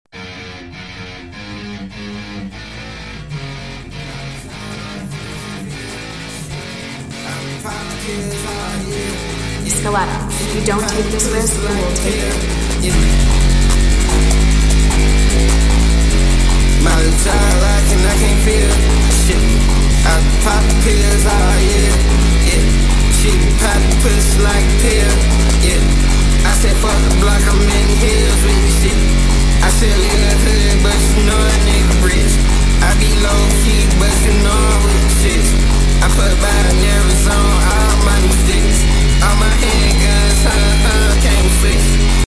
𝑮𝒖𝒊𝒕𝒂𝒓𝒆 𝑹𝒆𝒎𝒊𝒙